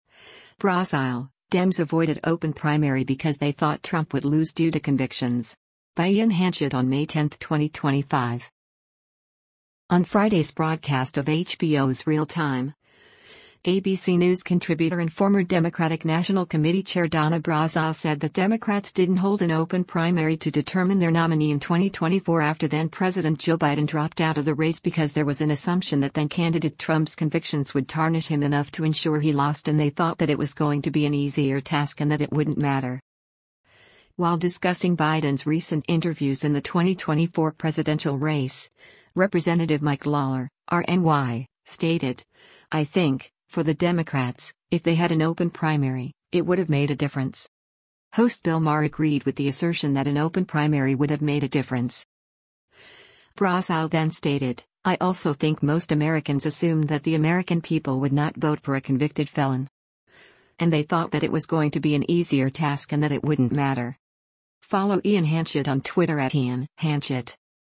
Host Bill Maher agreed with the assertion that an open primary would have made a difference.